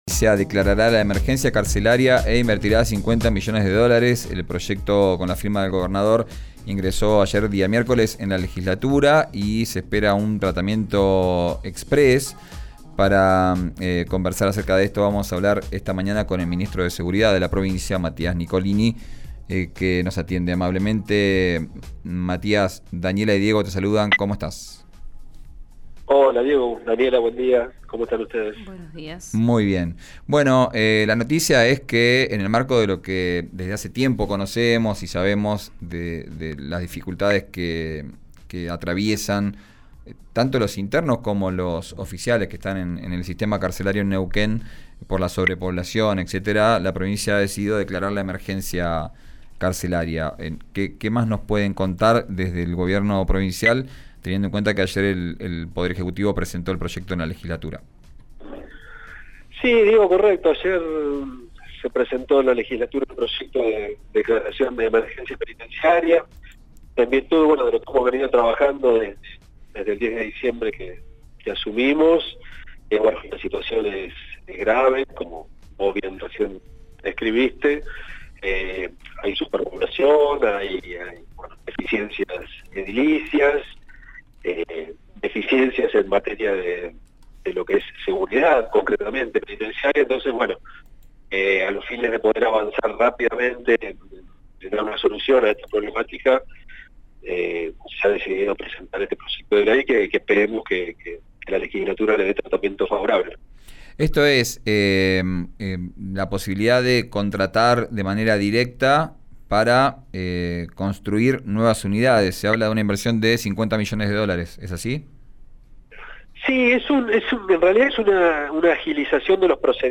Escuchá al ministro de Seguridad, Matías Nicolini en RÍO NEGRO RADIO: